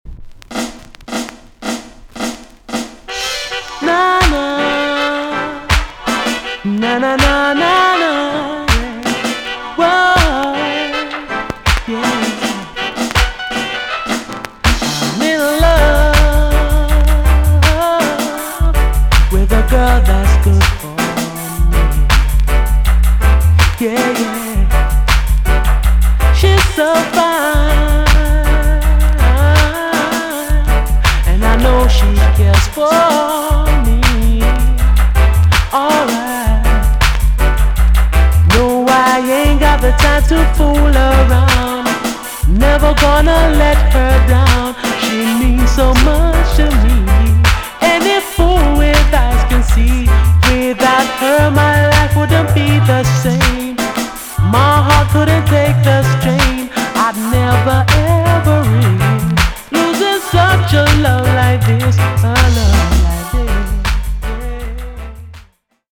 TOP >80'S 90'S DANCEHALL
EX-~VG+ 少し軽いチリノイズが入りますが良好です。
NICE SWEET VOCAL TUNE!!